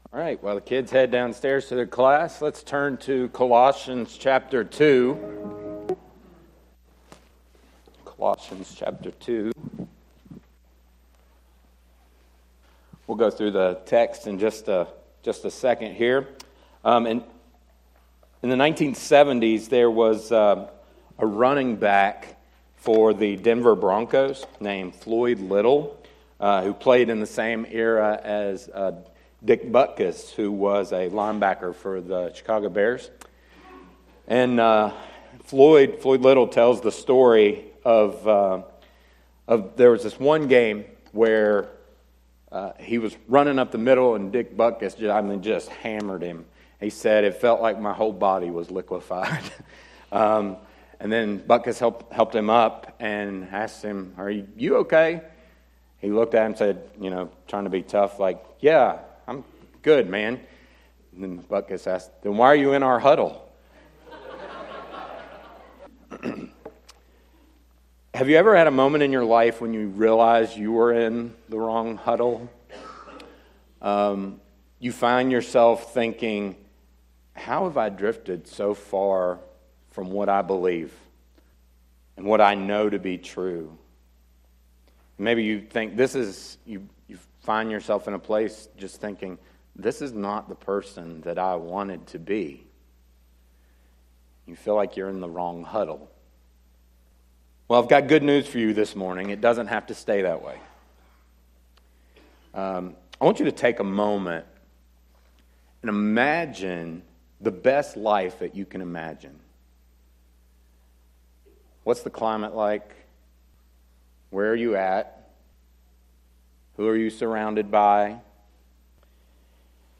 sermon-audio-trimmed.mp3